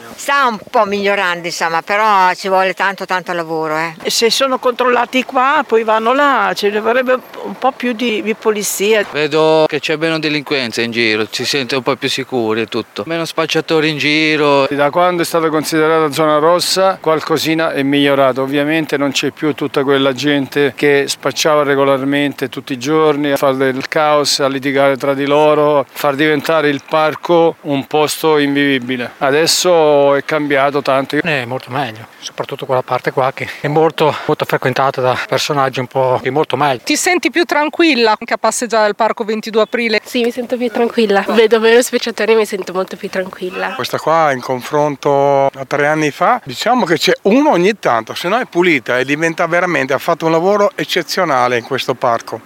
Primo bilancio in prefettura a Modena a un mese dall’introduzione delle zone rosse: più di 2500 le persone identificate, 48 gli ordini di allontanamento e 6 le persone arrestate. Un’ordinanza che sembra funzionare a detta dei residenti della Sacca che abbiamo intervistato nella zona del parco XXII Aprile.